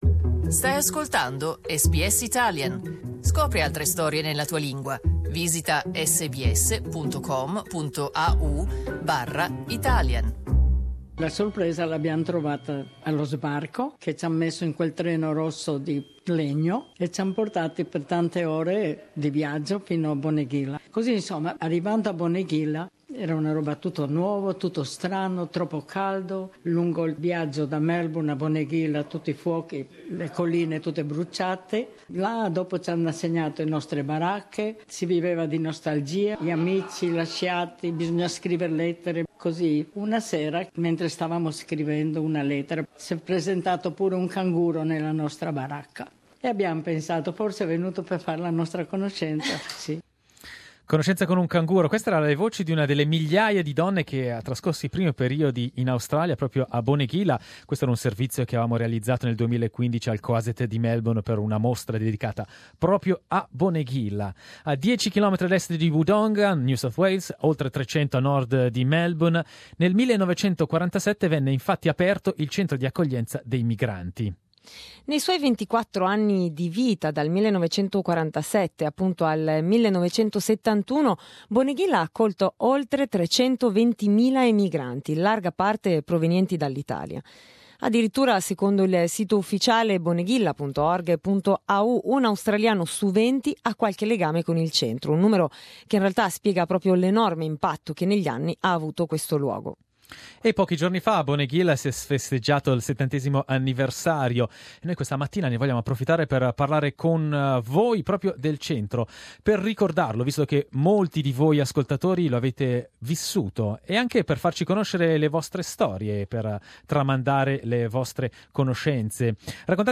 Questa mattina abbiamo aperto le linee per ricordare l'esperienza di Bonegilla, per ricordare con i nostri ascoltatori chi l'ha vissuta.